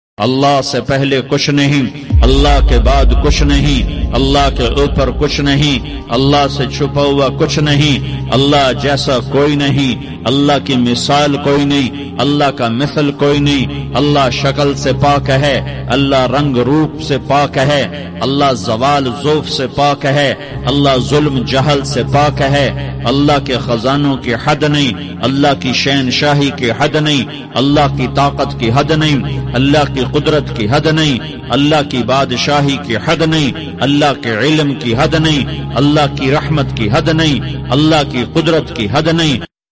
Maulana Tariq Jamil’s Beautifu Bayan sound effects free download